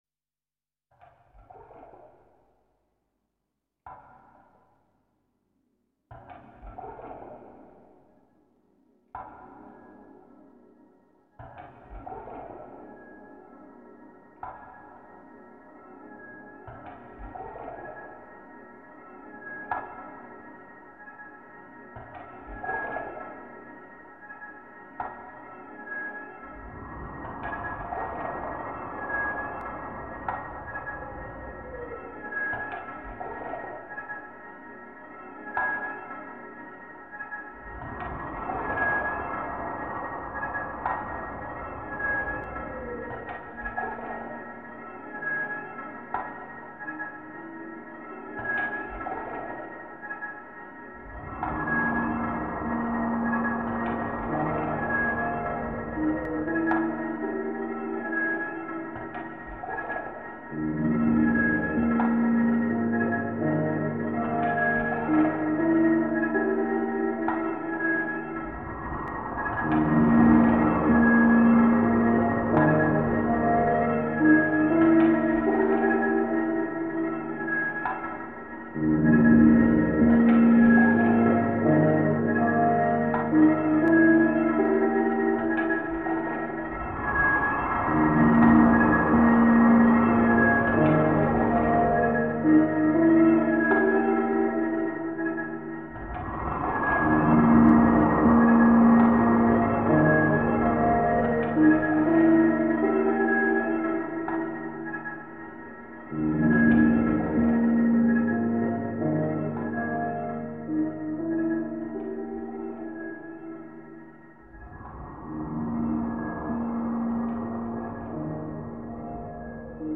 Recorded between 1990-1991 and original relehases on tapes.